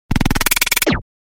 Distorted Glitch Sound Effect
Description: Distorted glitch sound effect. Sharp, short whoosh or error sound effect adds sudden motion and alerts your audience. It works perfectly for quick transitions, mistakes, or glitches in videos and games.
Distorted-glitch-sound-effect.mp3